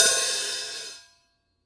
hihat_04.wav